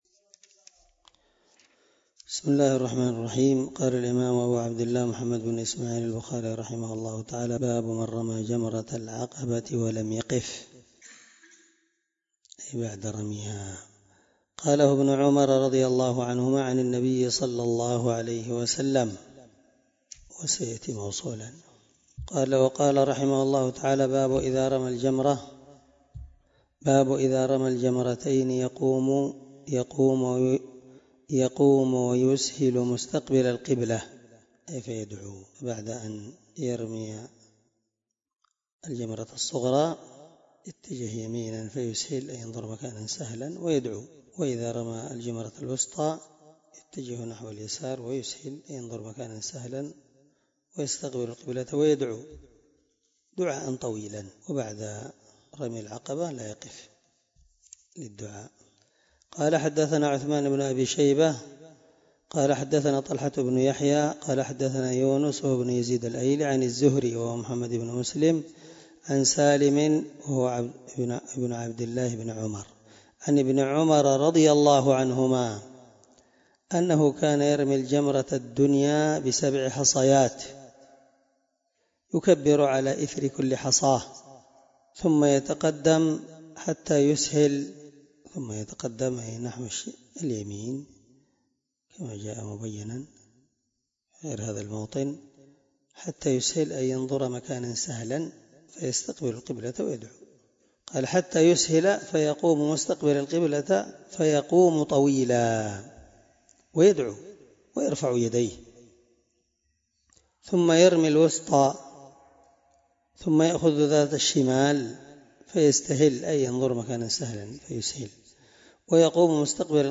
الدرس89 من شرح كتاب الحج حديث رقم(1751-1753 )من صحيح البخاري